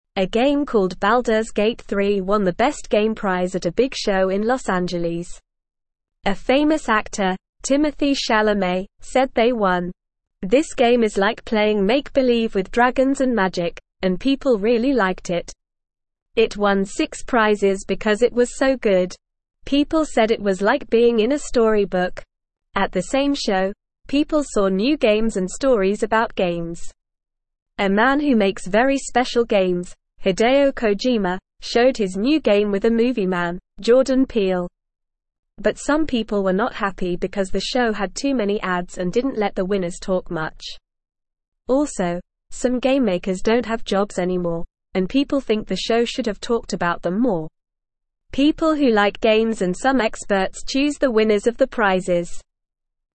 Normal
English-Newsroom-Beginner-NORMAL-Reading-Baldurs-Gate-3-Wins-Best-Game-Prize.mp3